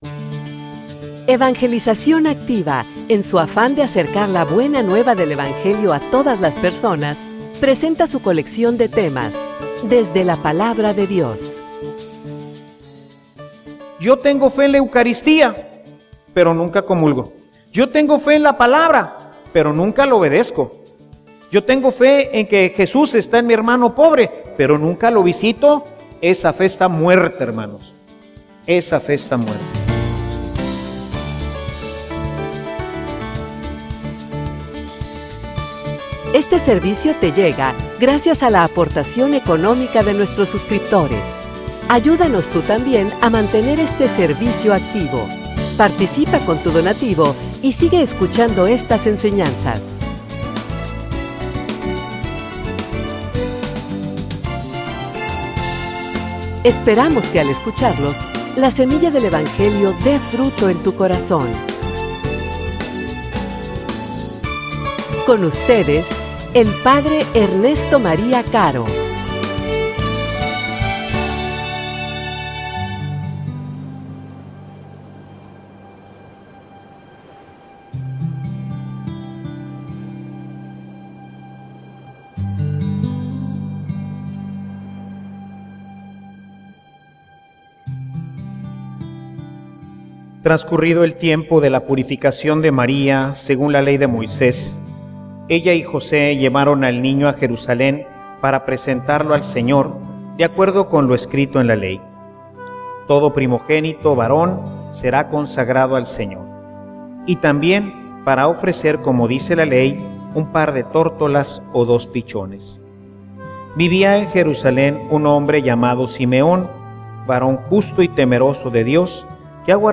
homilia_Mi_encuentro_con_Jesus.mp3